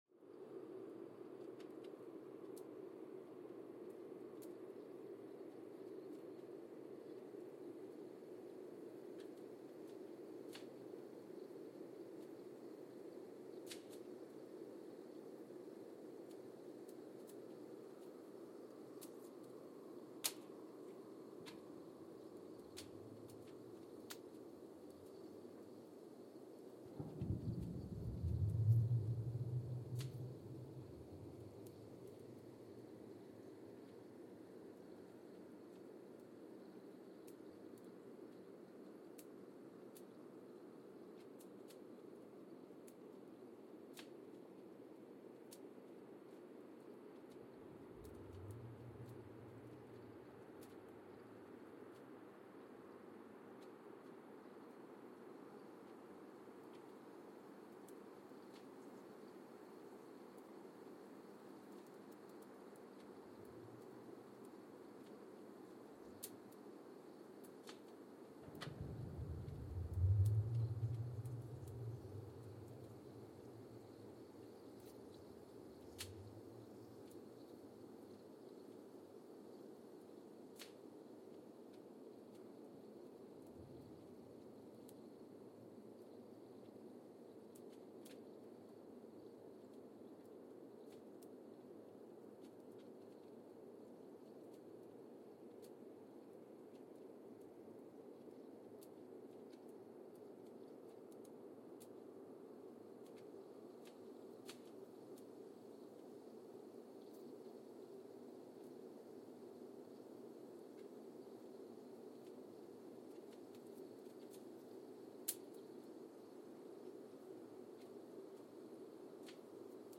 Sensor : Geotech KS54000 triaxial broadband borehole seismometer
Speedup : ×1,800 (transposed up about 11 octaves)
Gain correction : 20dB
SoX post-processing : highpass -2 90 highpass -2 90